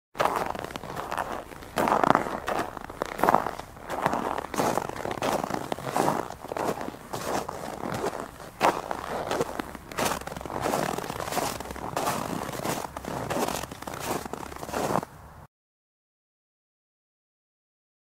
دانلود صدای راه رفتن کفش روی برف 1 از ساعد نیوز با لینک مستقیم و کیفیت بالا
جلوه های صوتی